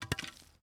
Bat Drop.wav